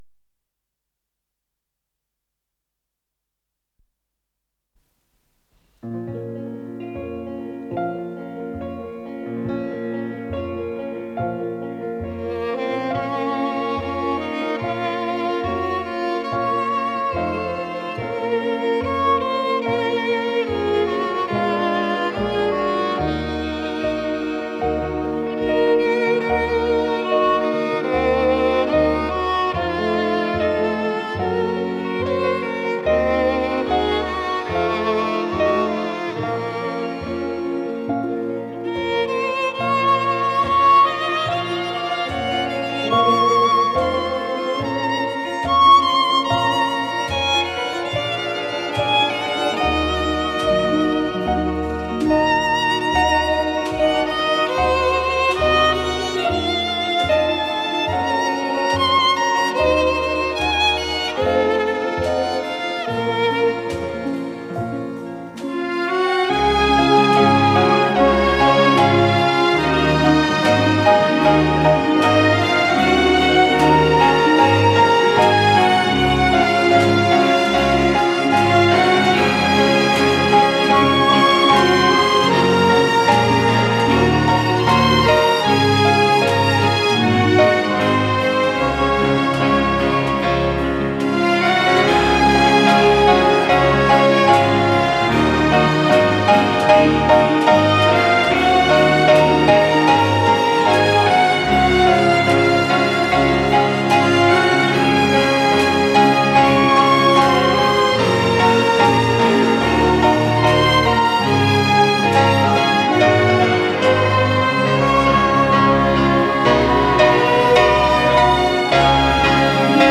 с профессиональной магнитной ленты
ПодзаголовокЗаставка, ми бемоль минор
ВариантДубль моно